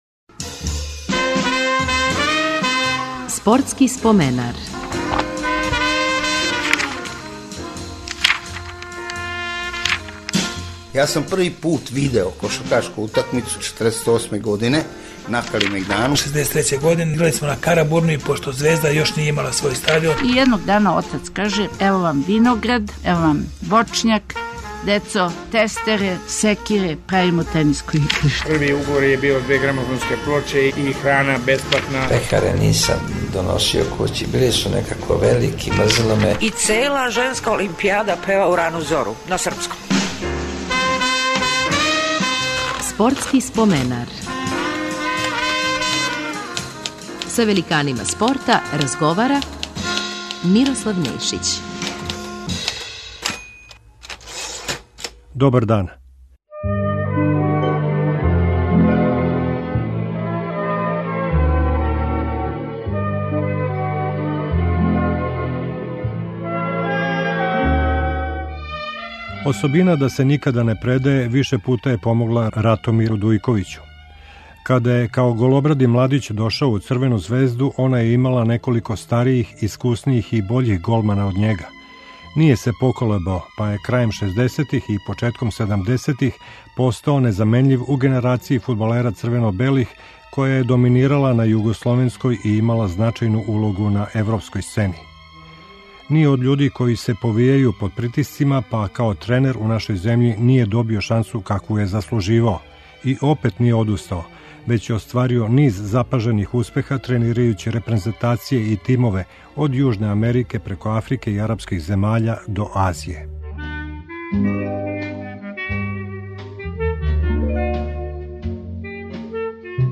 Гост ће бити голман Ратомир Дујковић.